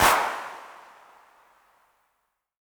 808CP_TapeSat_ST.wav